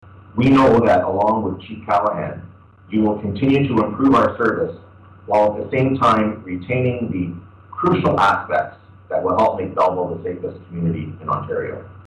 Deputy Chief Barry was sworn in by Mr. Justice Stephen Hunter at the new Belleville Police Service headquarters.
mayor-mitch-panciuk.mp3